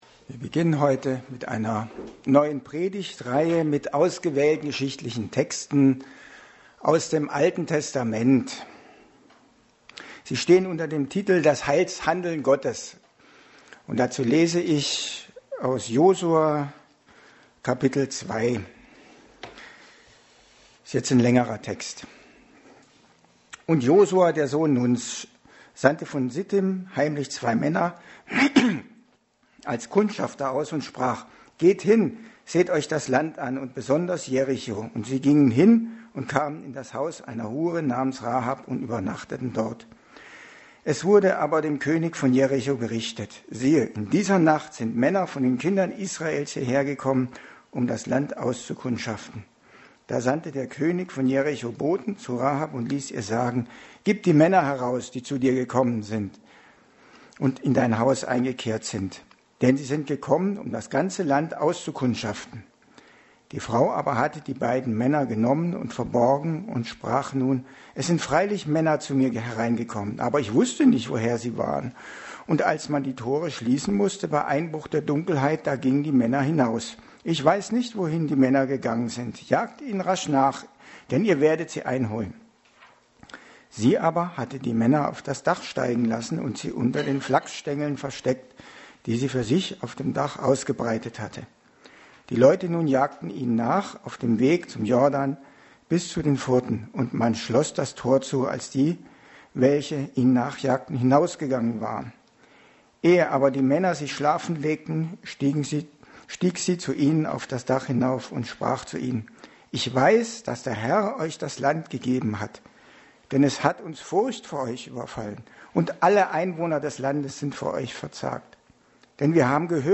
Passage: Josua 2 Dienstart: Predigt